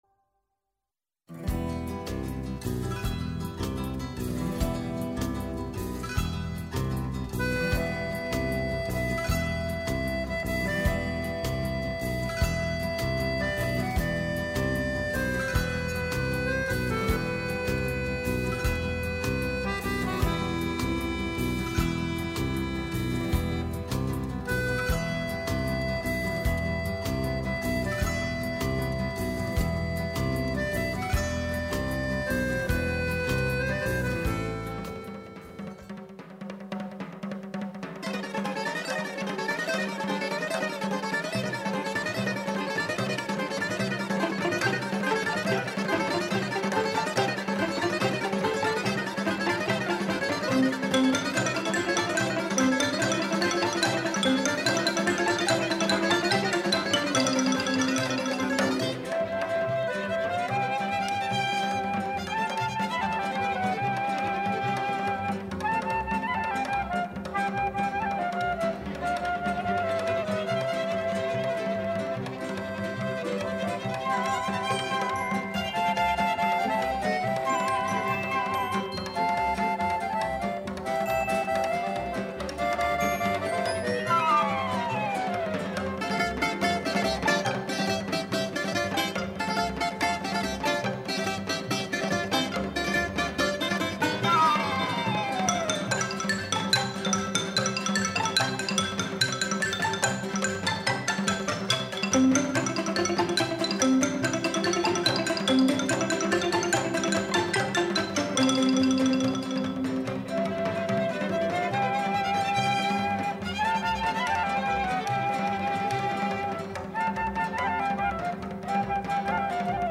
Ακούστηκαν μουσικές και τραγούδια του από τις ταινίες